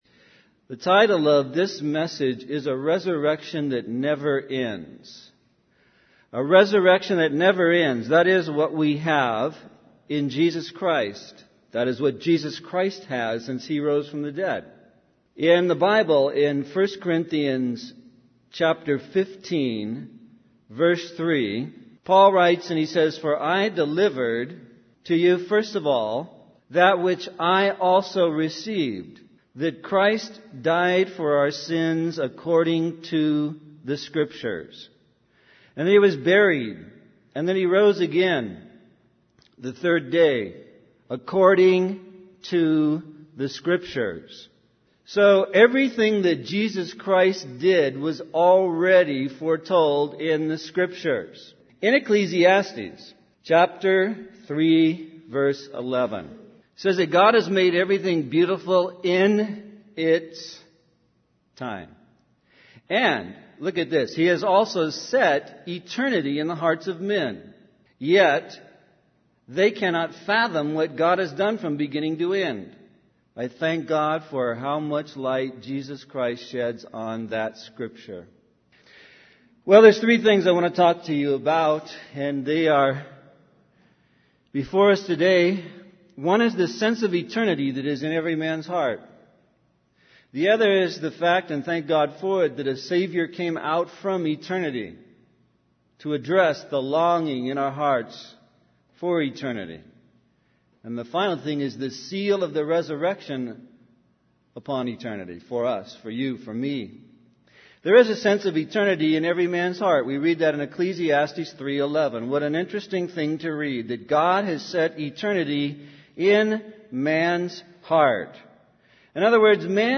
In this sermon, the speaker discusses the concept of eternity and how it is ingrained in the hearts of every human being. He explains that God has put eternity into man's heart, causing them to long for an eternal answer.